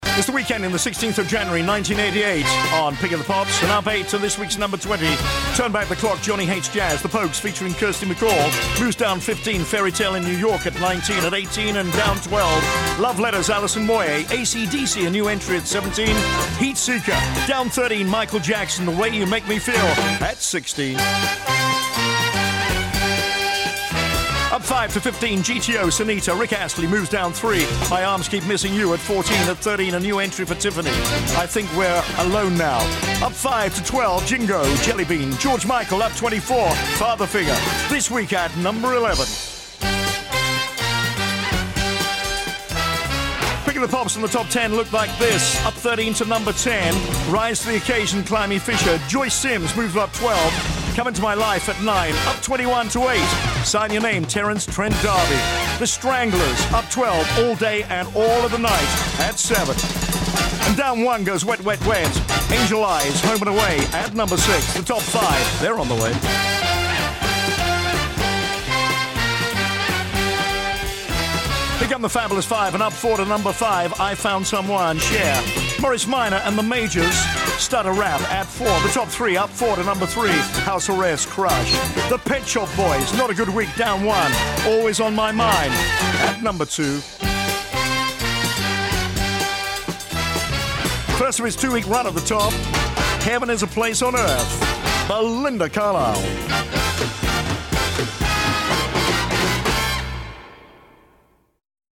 Here's the top twenty rundown for the UK Chart week ending 16th January 1988 featuring your number one, as broadcast in today's show.